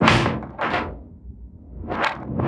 loop_perc2.flac